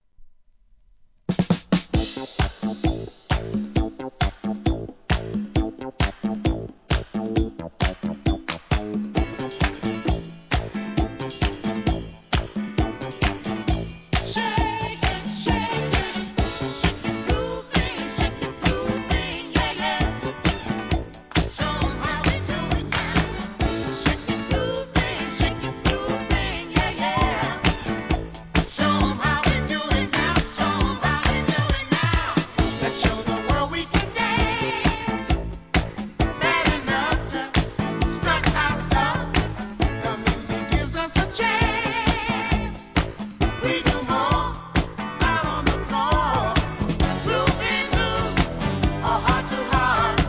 ¥ 550 税込 関連カテゴリ SOUL/FUNK/etc...